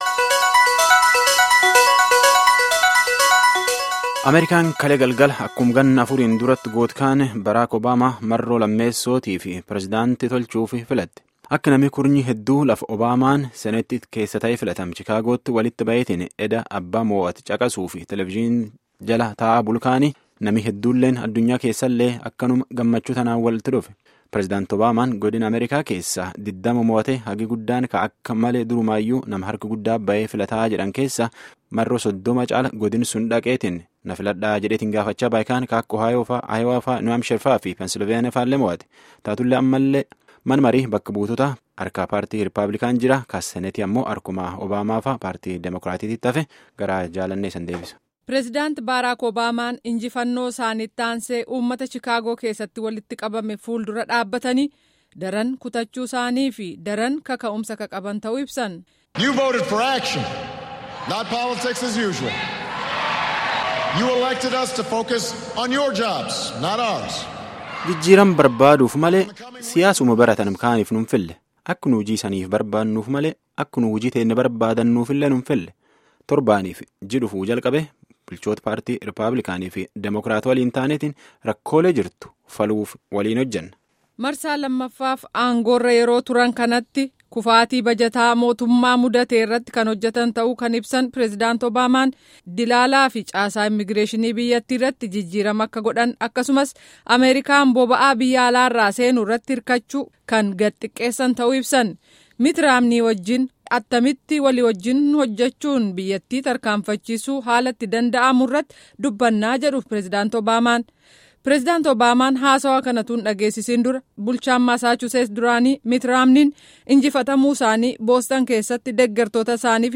gabaasa guutuu